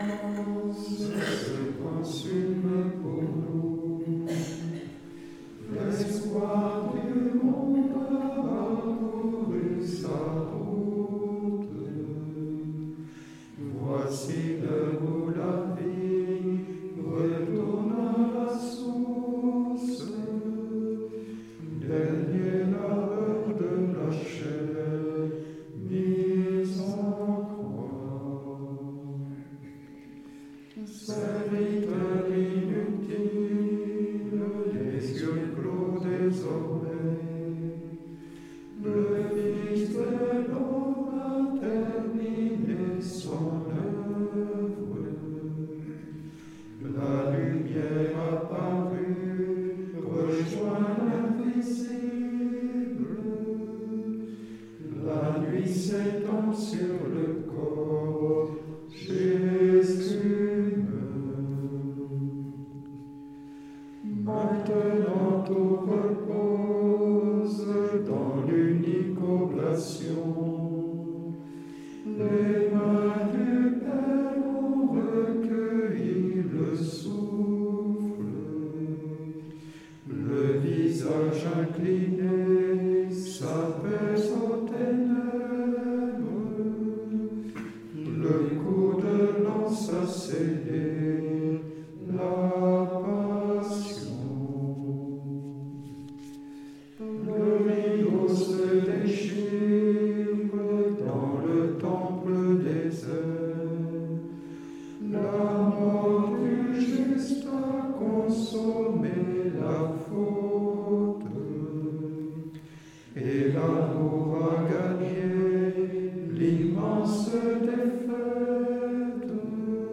En direct, depuis l’abbaye bénédictine de Tournay dans les Hautes-Pyrénées.